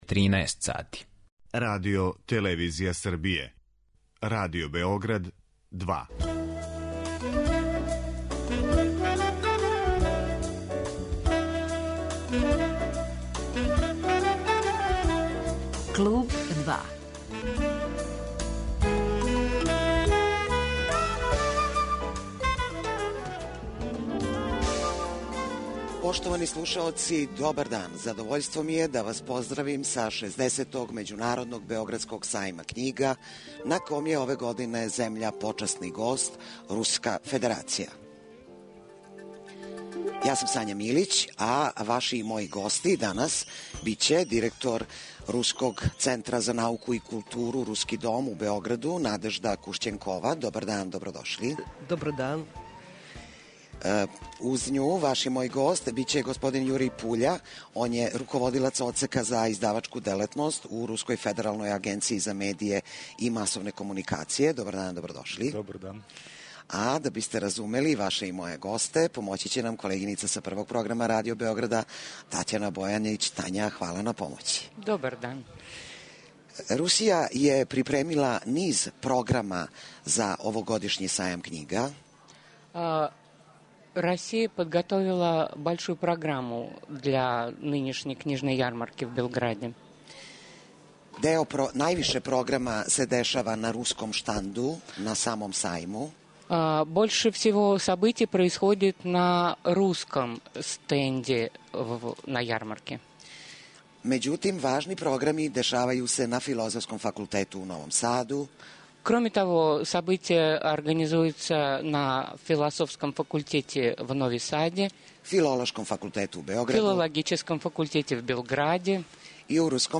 Директно са Сајма књига